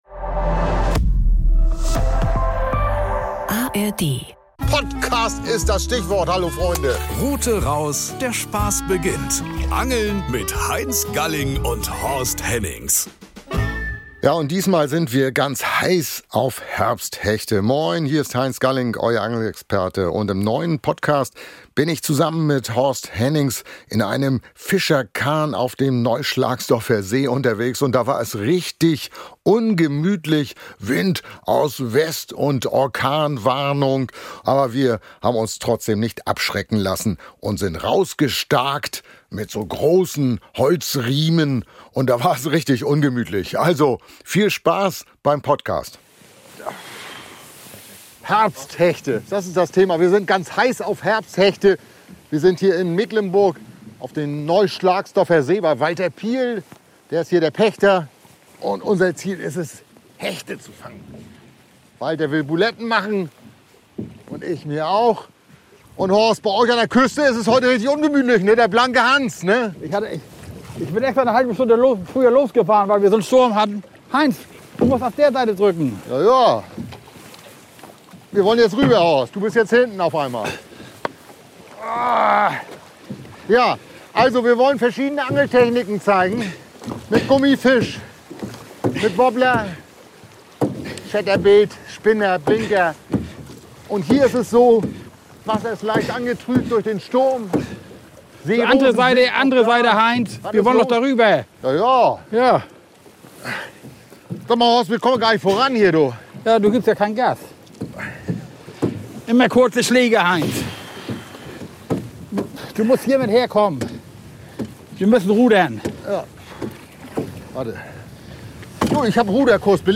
Auf dem Neuschlagsdorfer See zeigen sie vom alten Fischerkahn aus, welche Köder, welches Angelgerät und welche Taktiken auf dem stark verunkrauteten Gewässer wirklich funktionieren. Am nahe gelegenen, geheimnisvollen Waldsee wird es technisch: zwischen Erlenbüschen demonstrieren die Profis besondere Wurftechniken und Köderführungen, die beim Hechtangeln den Unterschied machen. Praktische Tipps, Hintergrundwissen und echte Fischergeschichten — Angel-Kino fürs Ohr an malerischen Gewässern in Mecklenburg-Vorpommern.